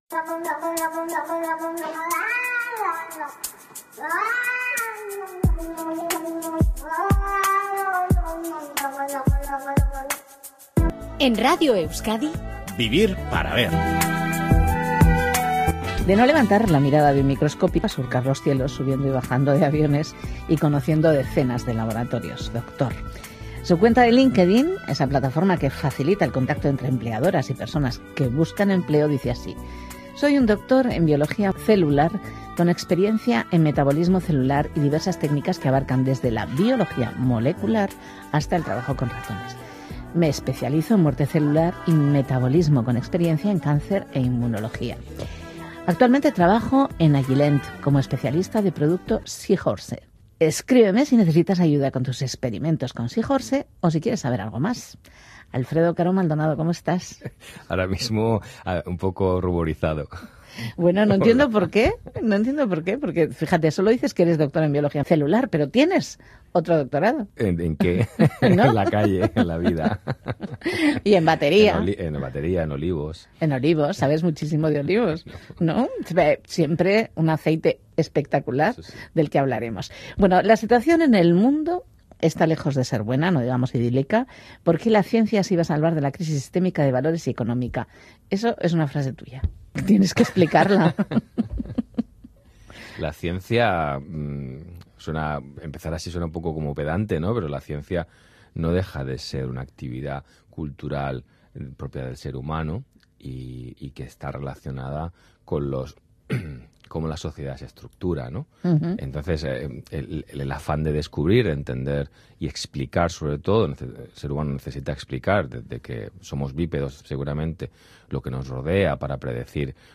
Con música del entrevistado. La entrevista más personal que le han hecho.